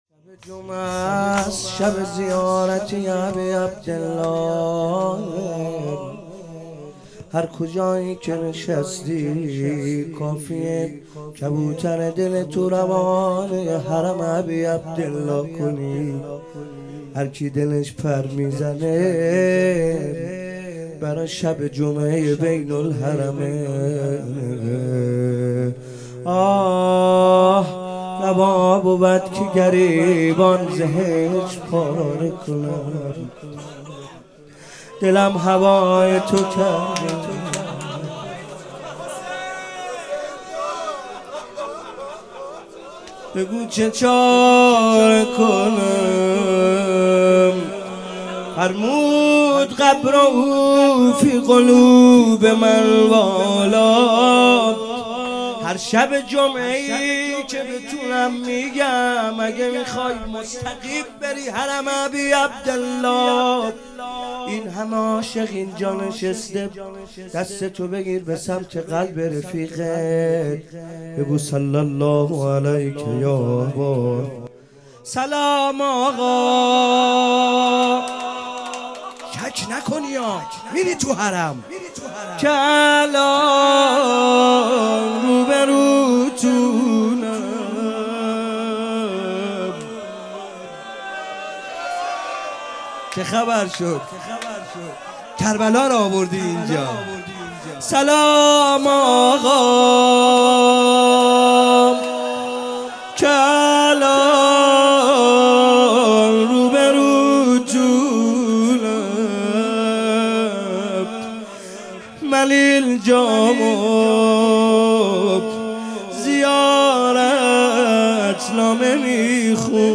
فاطمیه97 - شب دوم - مناجات - روا بود که گریبان ز حجر پاره کنم